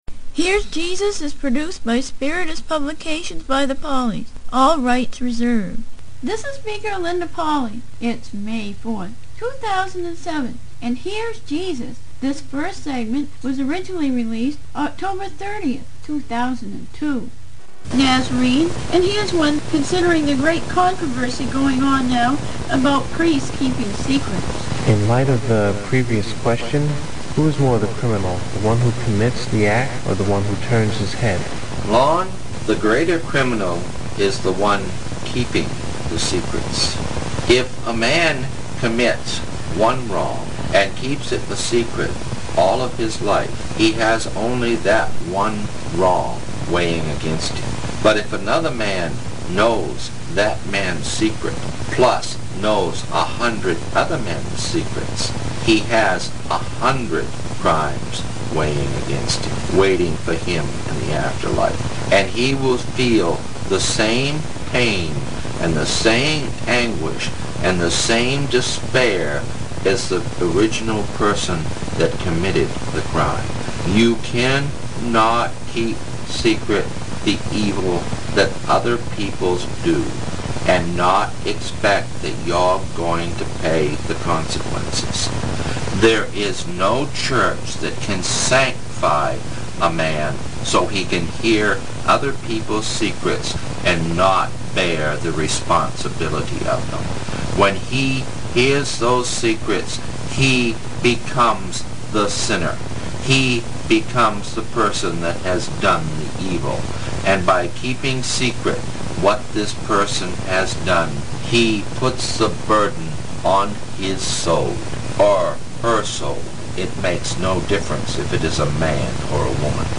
Interviews With
Channeled Through Internationally Known Psychic